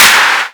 edm-clap-16.wav